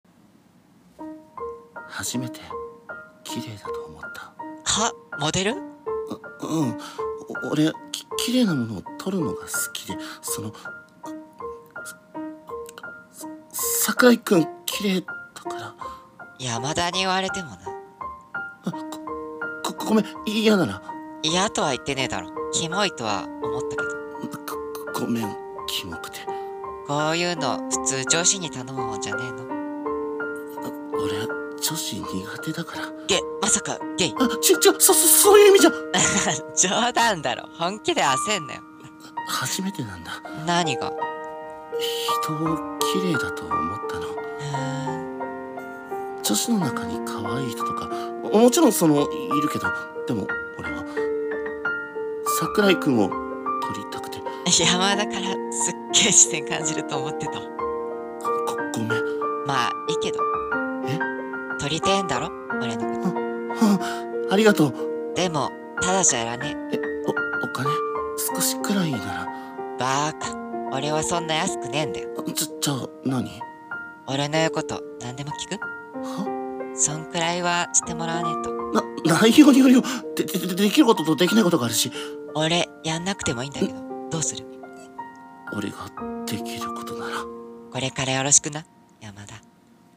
【BL声劇】綺麗な君に恋をした・第1話